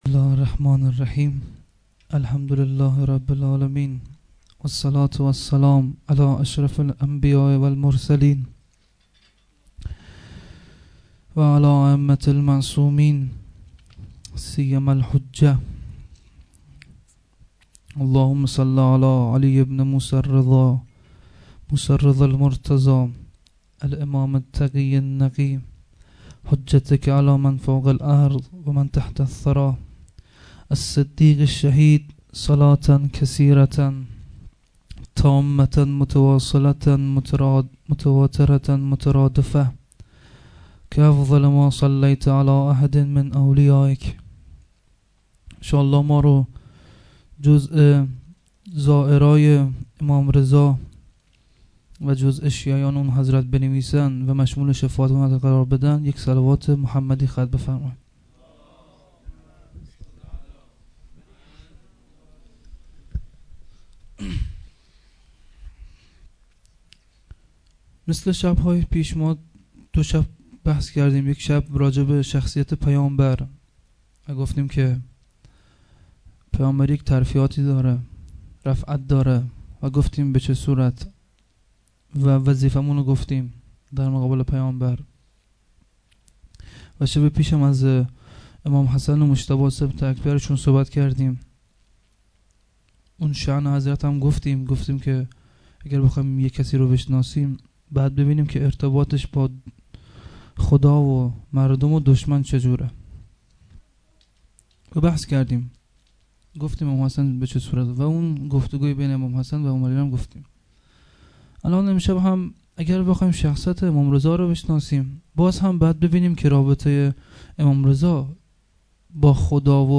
shahadat-emam-reza-92-sokhanrani-2.mp3